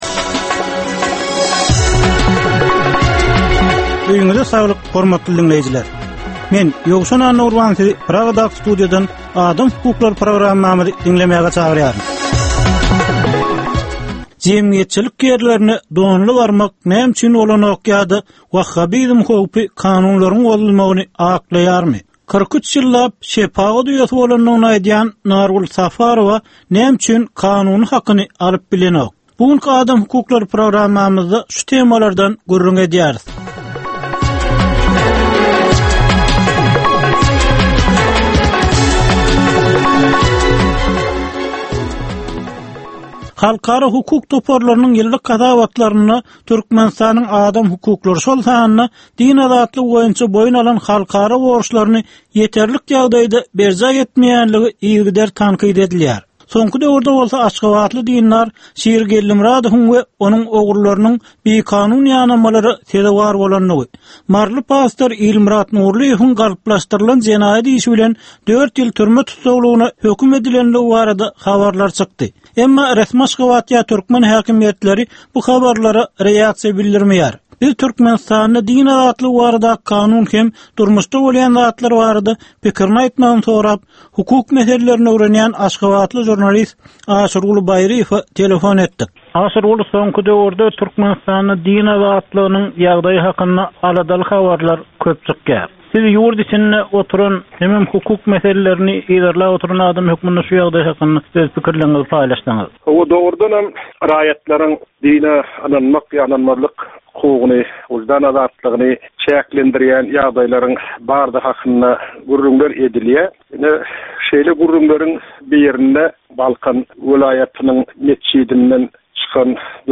Türkmenistandaky adam hukuklarynyň meseleleri barada ýörite programma. Bu programmada adam hukuklary bilen baglanyşykly anyk meselelere, problemalara, hadysalara we wakalara syn berilýär, söhbetdeşlikler we diskussiýalar gurnalýar.